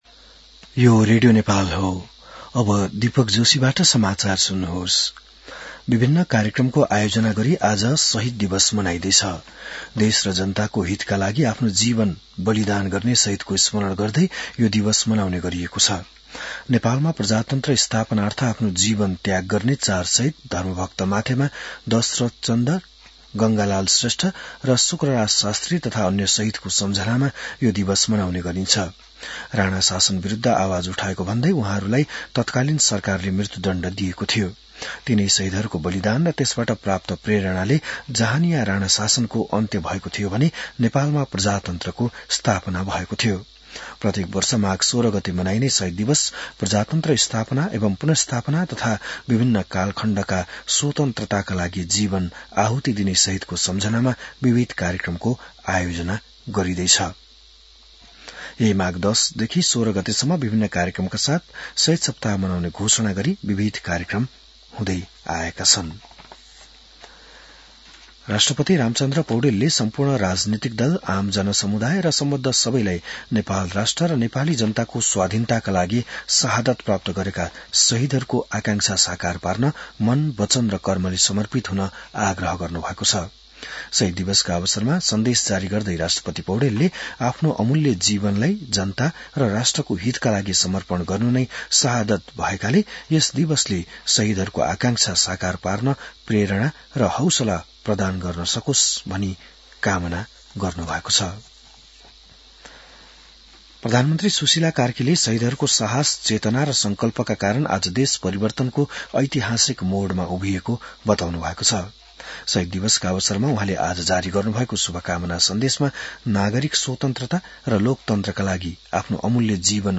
बिहान १० बजेको नेपाली समाचार : १६ माघ , २०८२